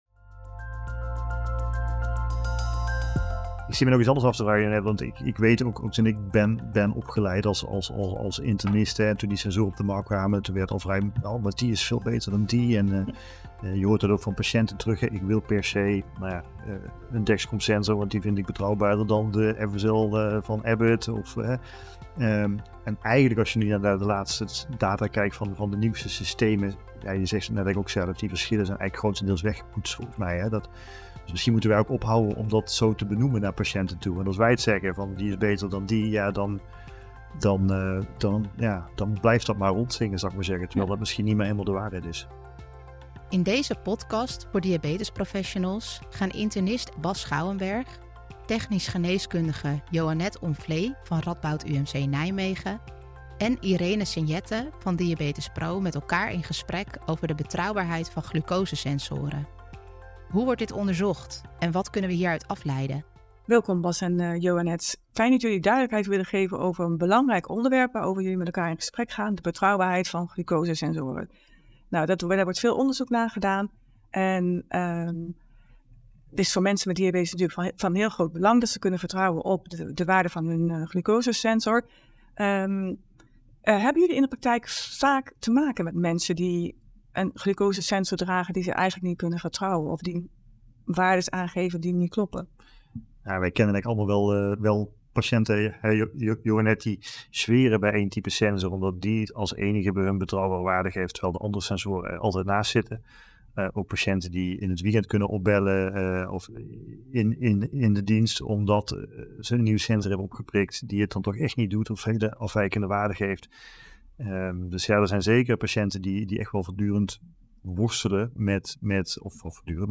met elkaar in gesprek over de betrouwbaarheid van glucosesensoren